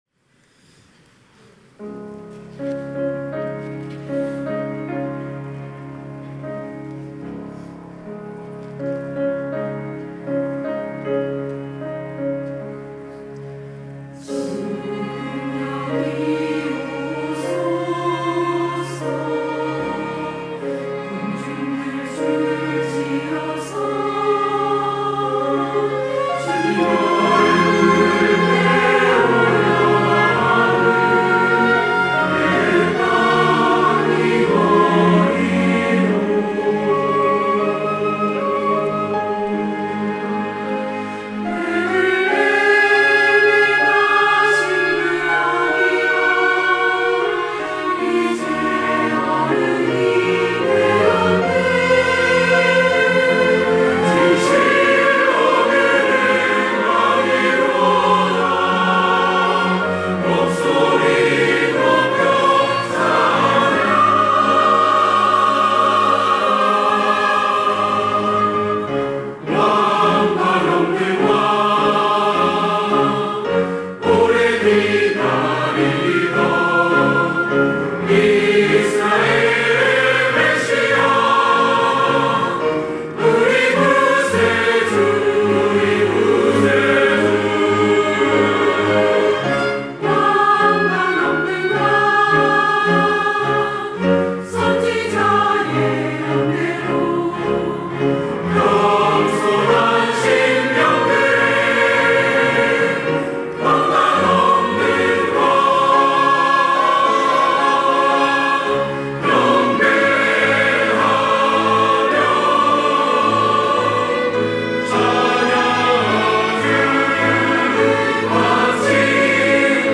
호산나(주일3부) - 이 때에 오소서, 호산나!
찬양대 호산나